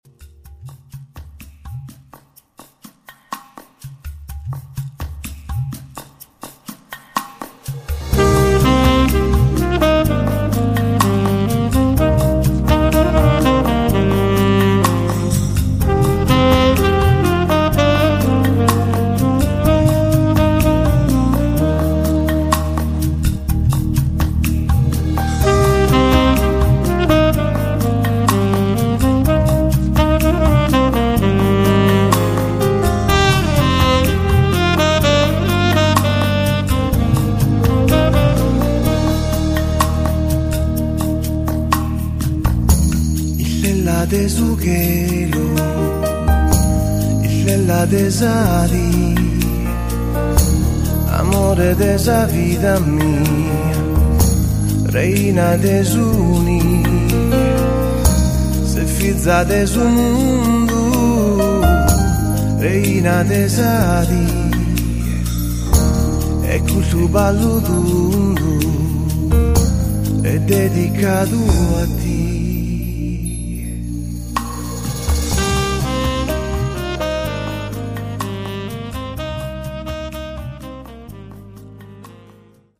Vocals, Djembé, Percussioni, Batteria, Synth